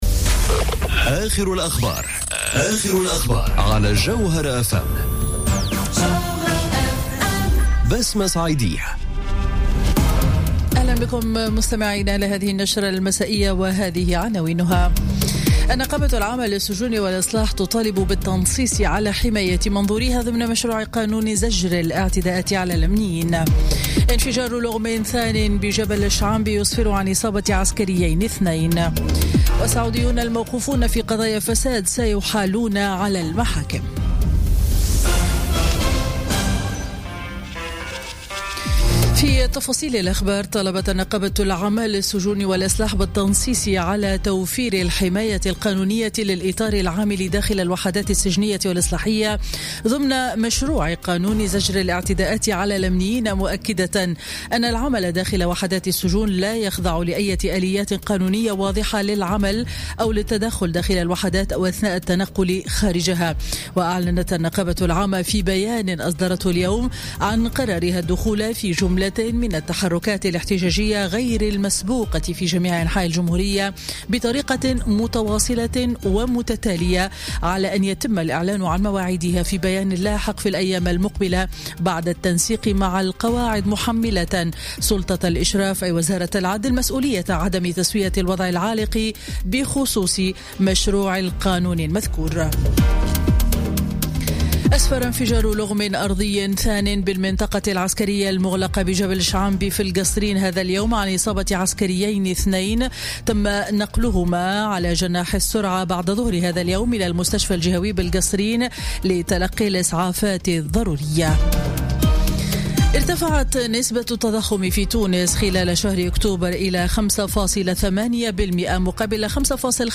أخبار السابعة مساء ليوم الاثنين 06 نوفمبر 2017